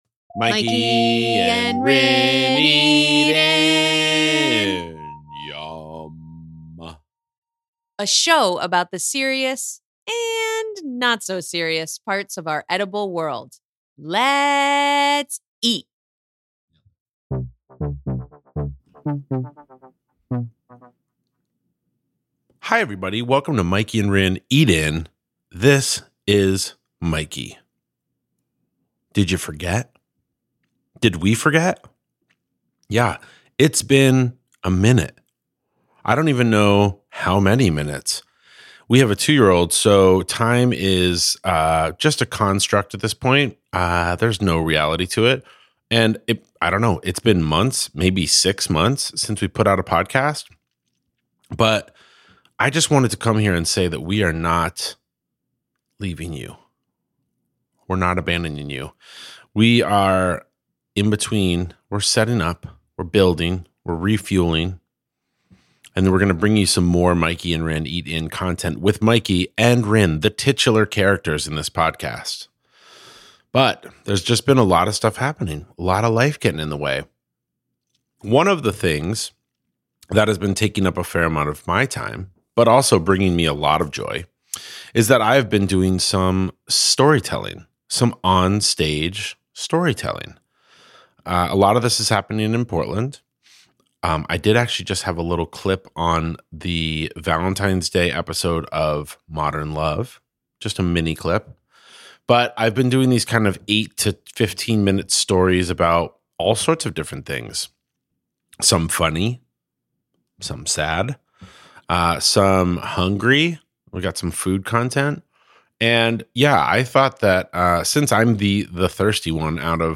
we eat. you listen.
Lots of swearing and stupid humor served as condiments.